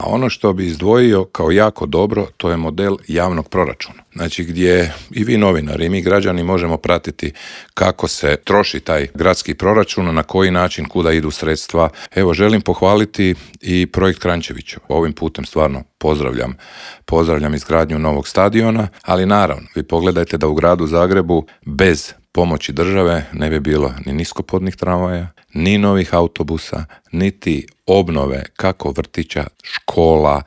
ZAGREB - U Intervjuu Media servisa gostovao je predsjednik zagrebačkog HDZ-a Ivan Matijević s kojim smo prošli aktualne teme na nacionalnoj, kao i na zagrebačkoj razini.